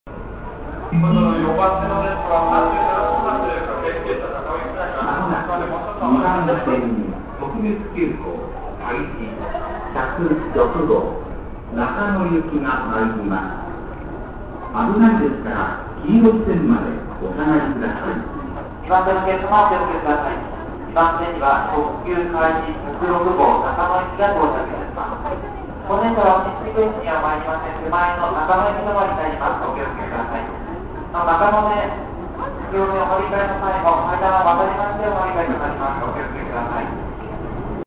接近警告「特別急行かいじ106号」中野行き